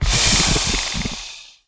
Index of /App/sound/monster/misterious_diseased_bow